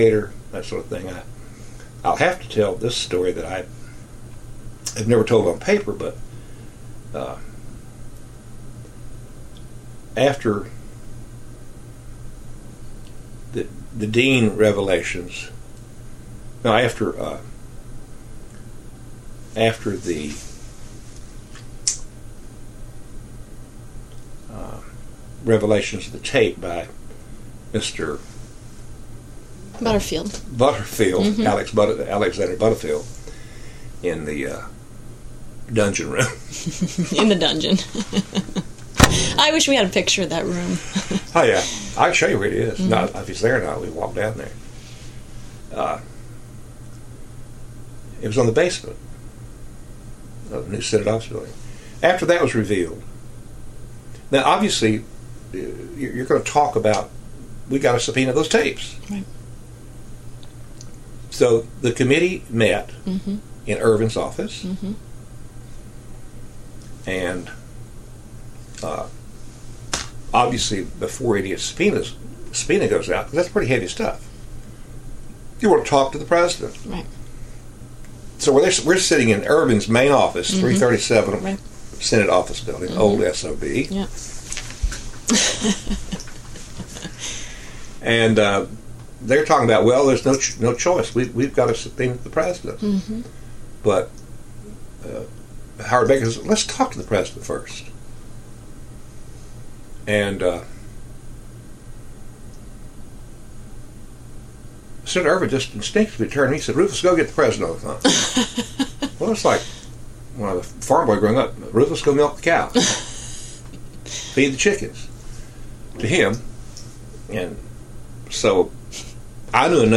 Oral History Excerpt | Edmisten to Richard Nixon: “Senator Ervin Wants to Get You”
Rufus Edmisten, Deputy Chief Counsel, Senate Watergate Committee